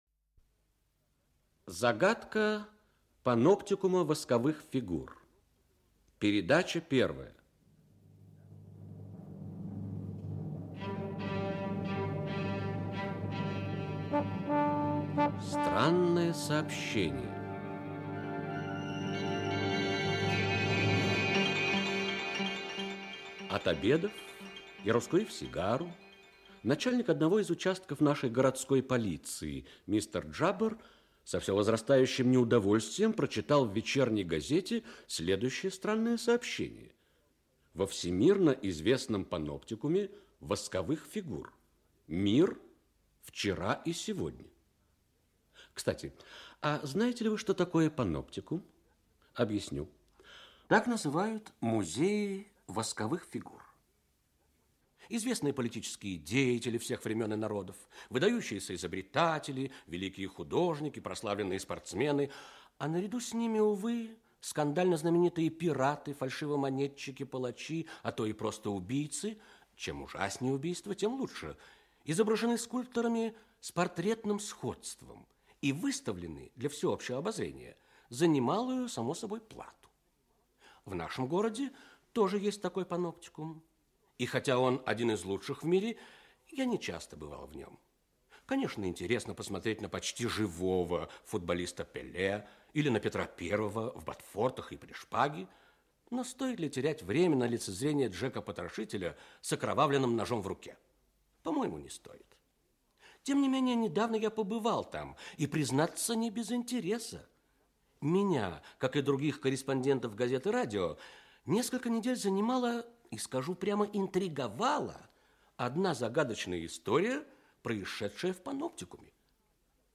Аудиокнига Загадка паноптикума восковых фигур. Часть 1. Странное сообщение | Библиотека аудиокниг